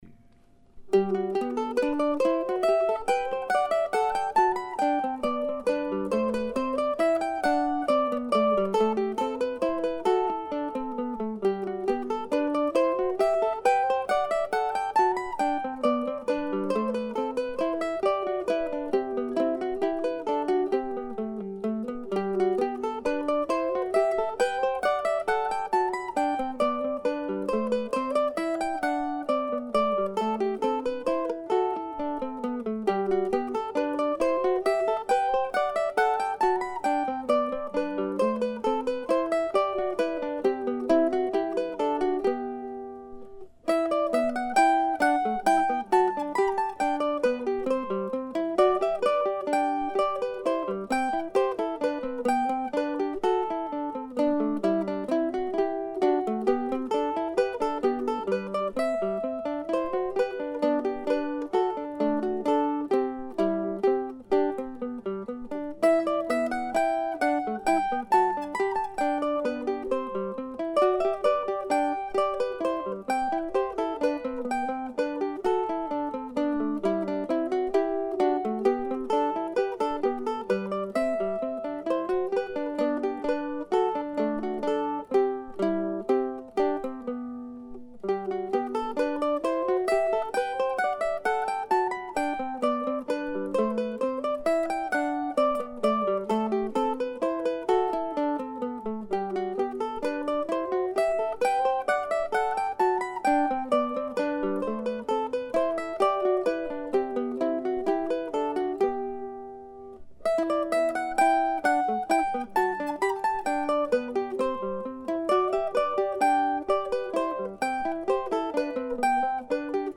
January 22, 2005 (duo in 3/4) (
"January 22" is a waltz of sorts but I thought it would be fun to add a moving second part.
These three pieces were all recorded this morning with cold winds blowing outside.